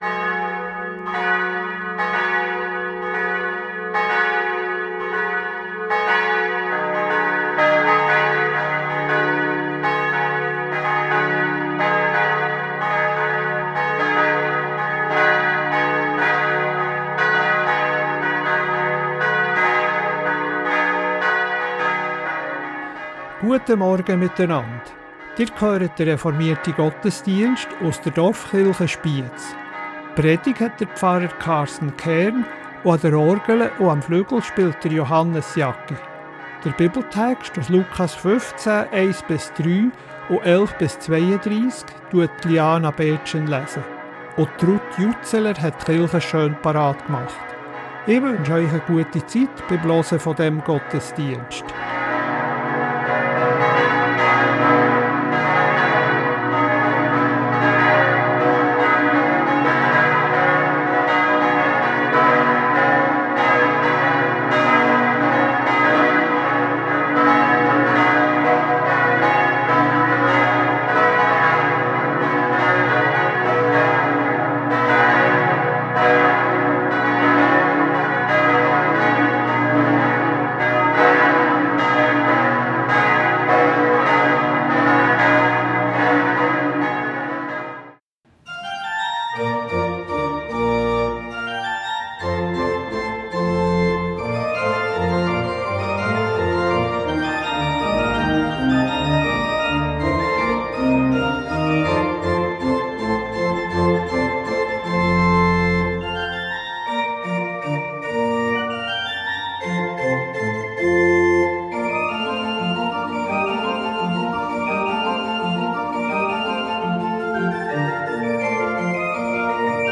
Reformierte Dorfkirche Spiez ~ Gottesdienst auf Radio BeO Podcast